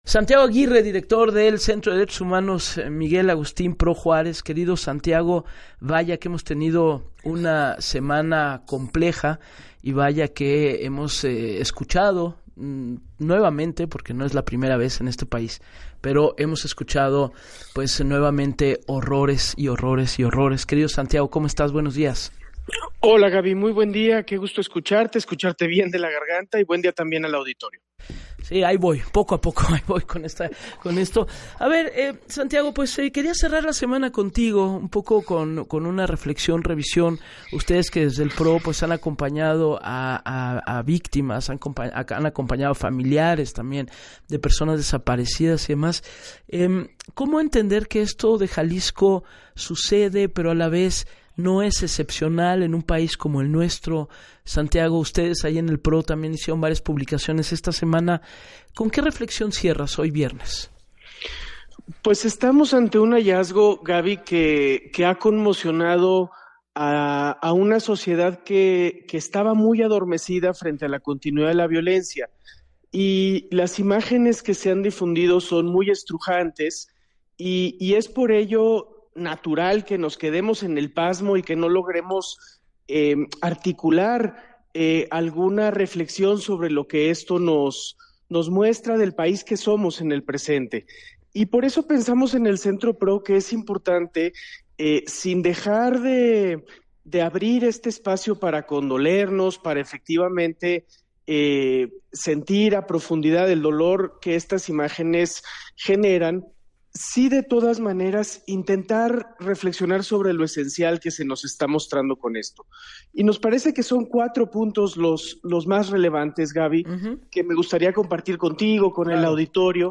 En entrevista con Gabriela Warkentin